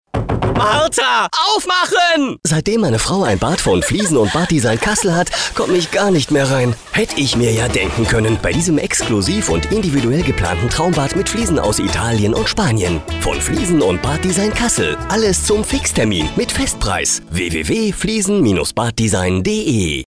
deutscher Sprecher.
Sprechprobe: eLearning (Muttersprache):
german voice over artist